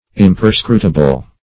Search Result for " imperscrutable" : The Collaborative International Dictionary of English v.0.48: Imperscrutable \Im`per*scru"ta*ble\, a. [L. imperscrutabilis.] Not capable of being searched out; inscrutable.